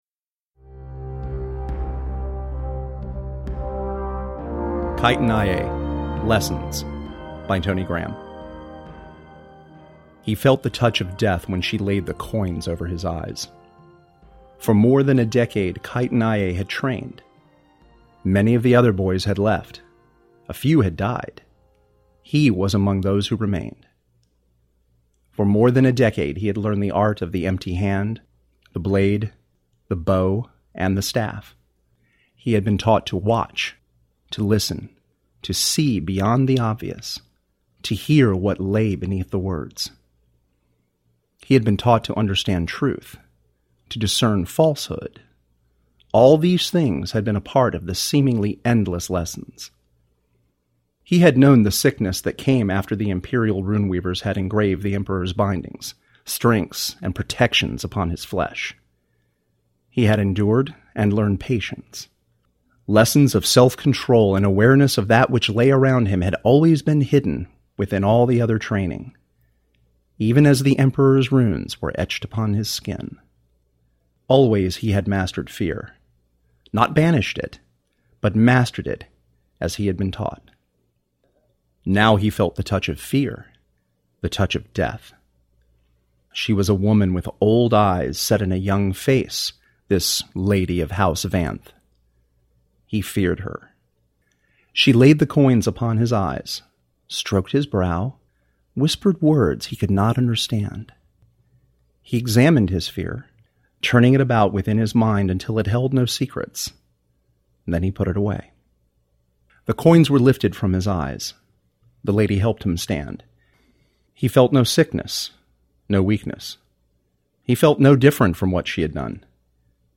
A performance of the flash fiction story Kytun Iye: Lessons (approximately 4 minutes).
All music by Kevin MacLeod.